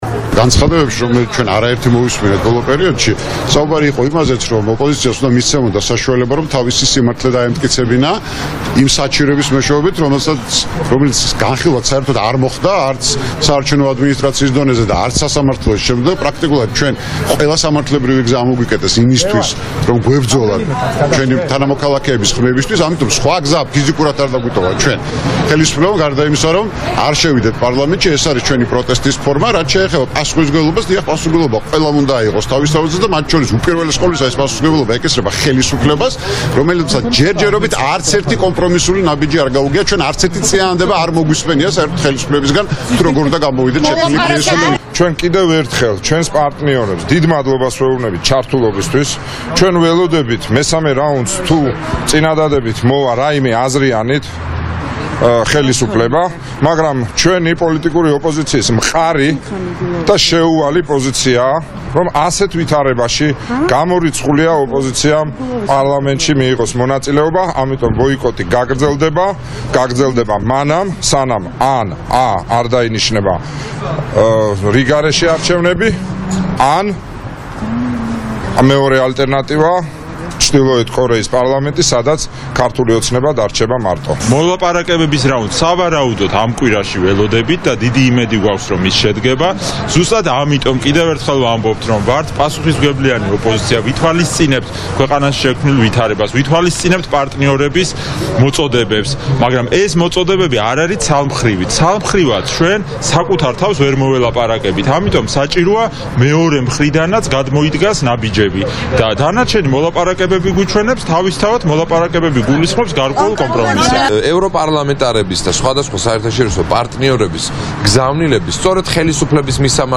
მოვისმინოთ ოპოზიციის ლიდერების: ზაალ უდუმაშვილის,გიგი უგულავას,სერგო ჩიხლაძის, საბა ბუაძის კომენტარები